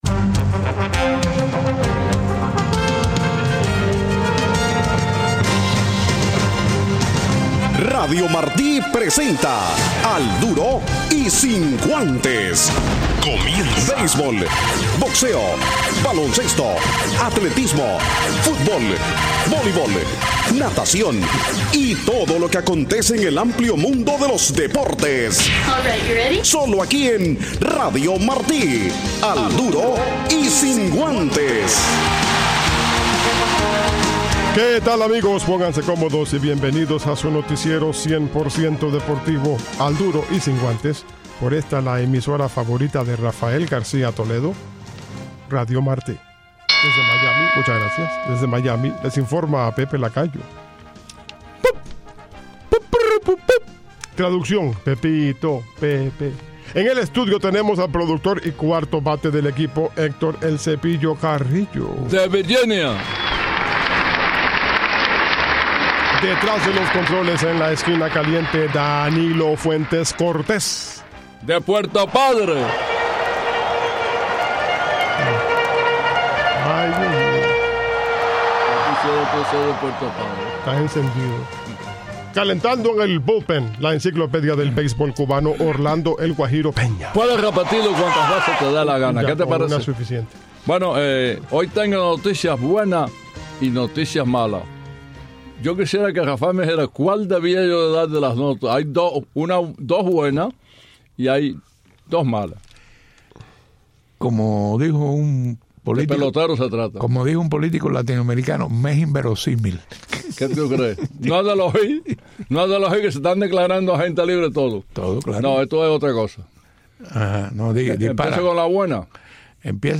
una entrevista